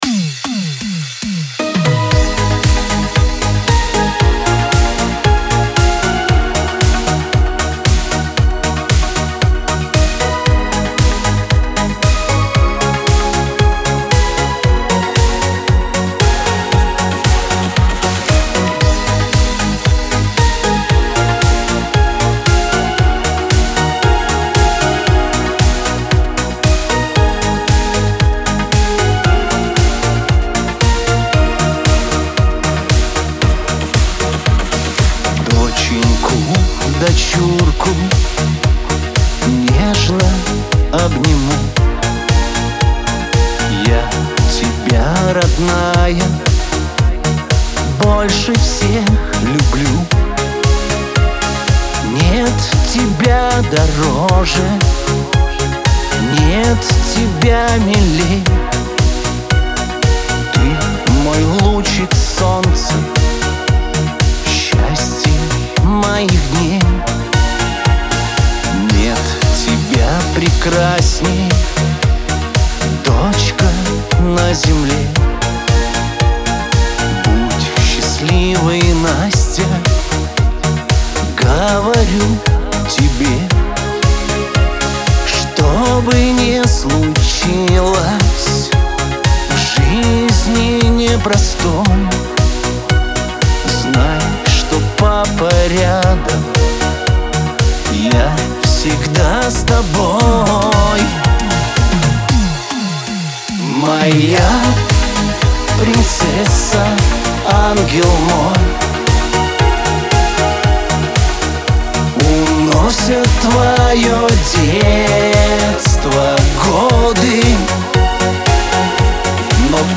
pop , Лирика